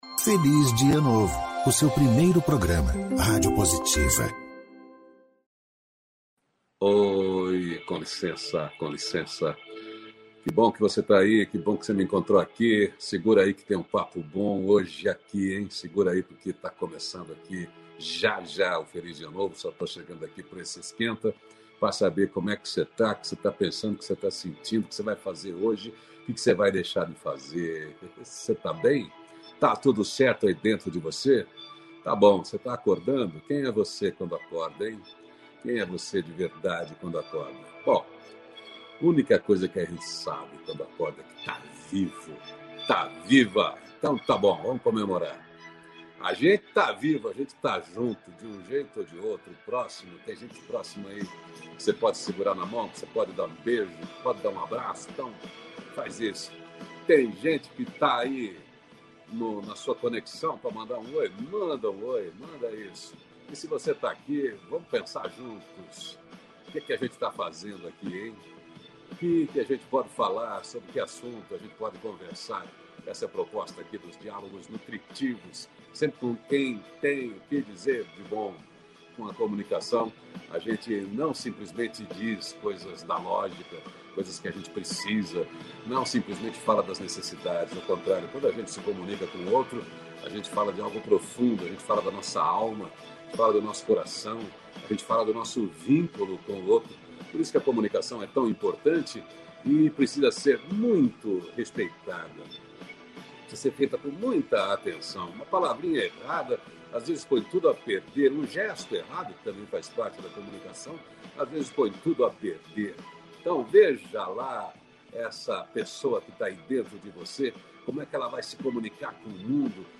A conversa hoje é sobre comunicação consciente